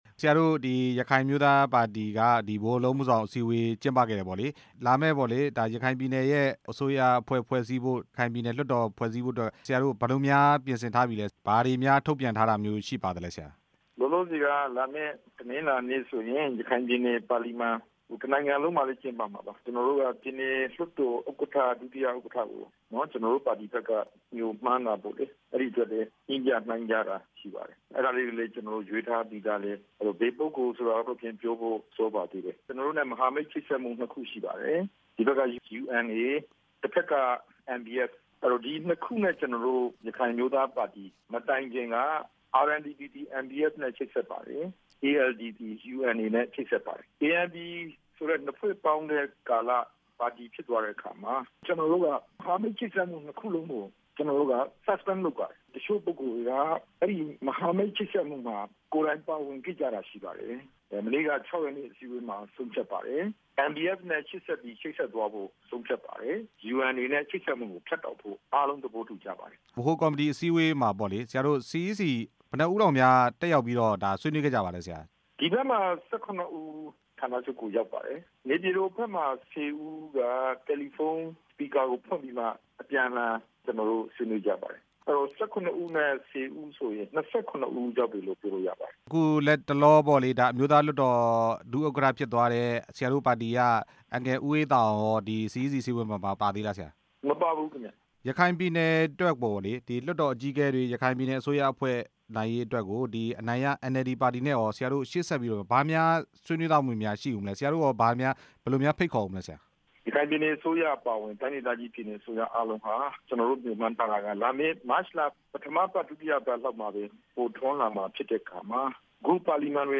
ရခိုင်အမျိုးသားပါတီရဲ့ ဥက္ကဌ ဒေါက်တာအေးမောင်နဲ့ မေးမြန်းချက်